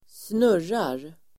Uttal: [²sn'ur:ar]